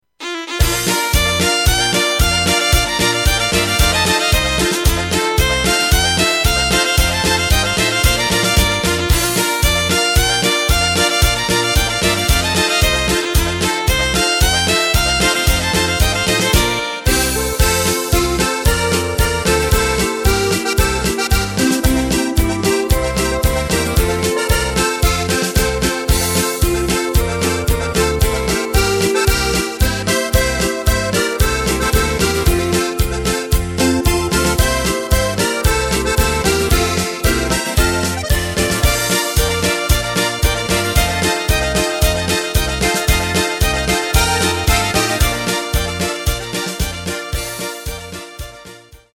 Takt:          2/4
Tempo:         113.00
Tonart:            Bb
Boarischer aus dem Jahr 2009!